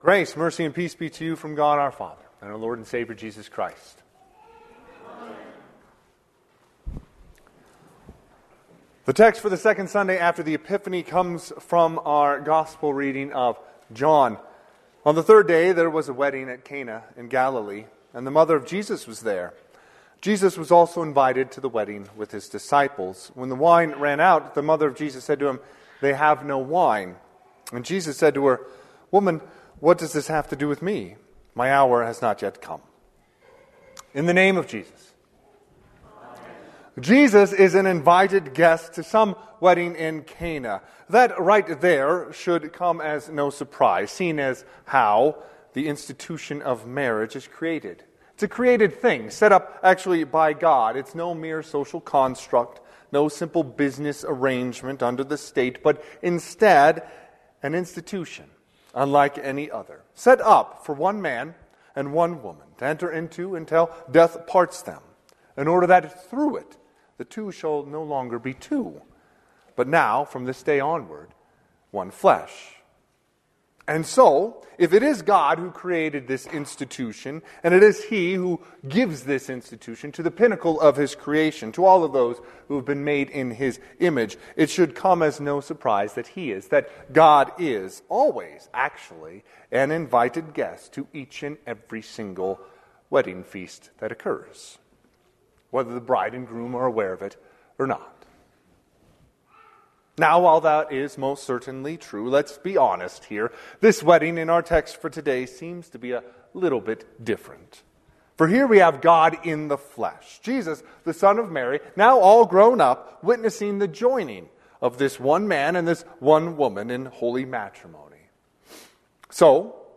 Sermon - 1/19/2025 - Wheat Ridge Lutheran Church, Wheat Ridge, Colorado
Second Sunday after the Epiphany